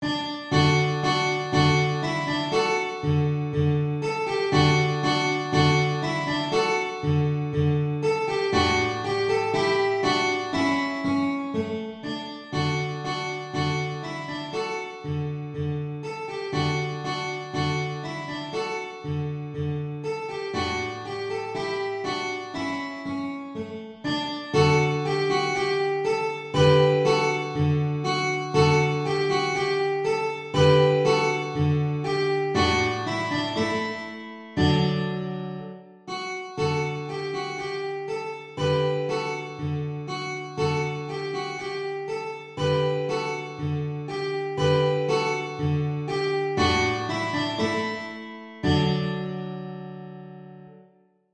Free Easy Guitar Solo